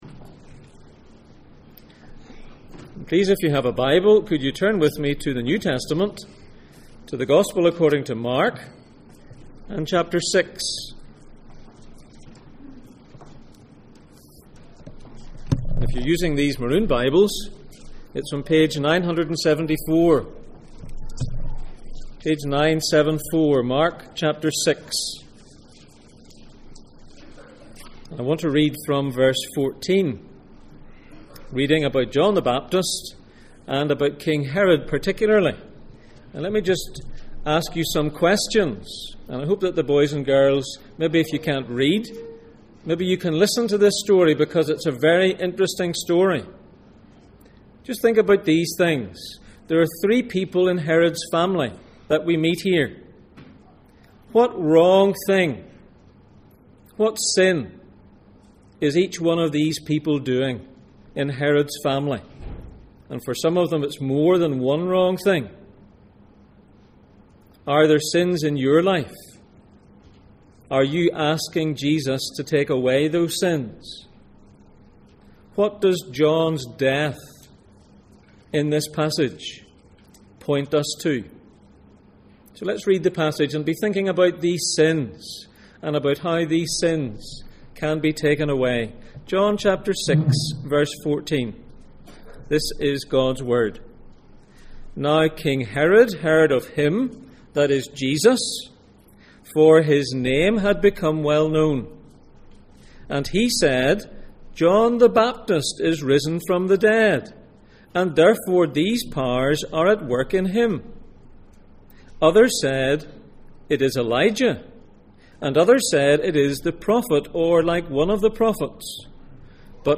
Passage: Mark 6:14-29, Luke 23:8 Service Type: Sunday Morning